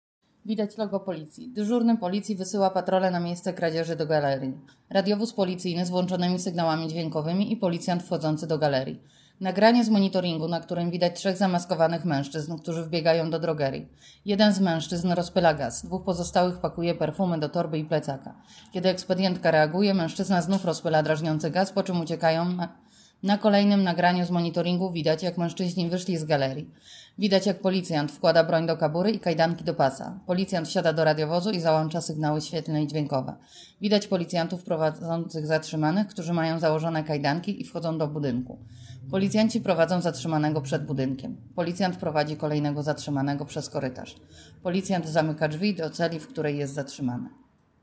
Nagranie audio Audiodeskrypcja.mp3